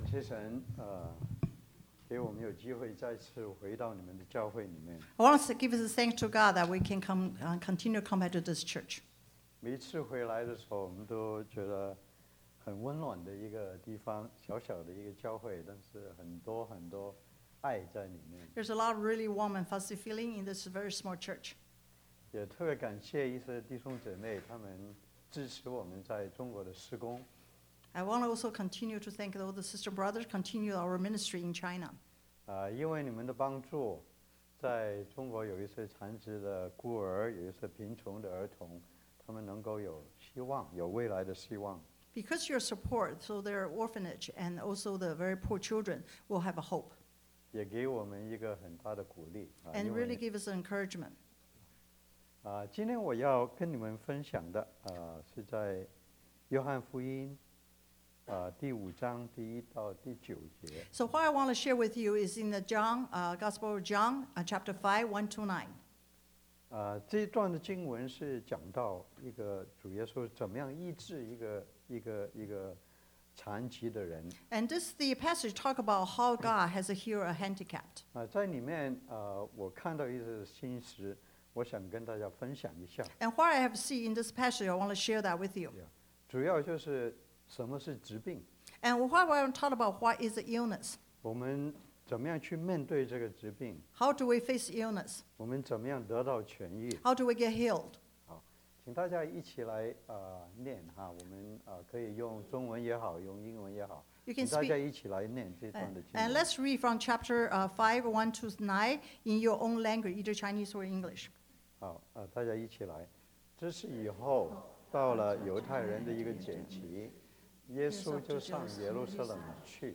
Sermon Audio Bilingual Sermon